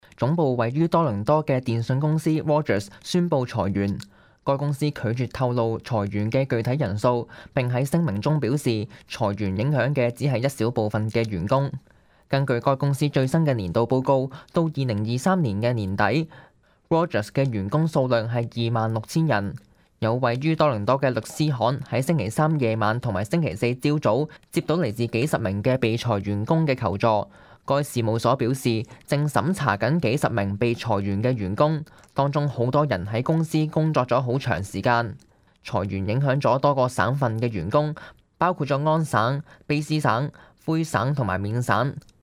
news_clip_22555.mp3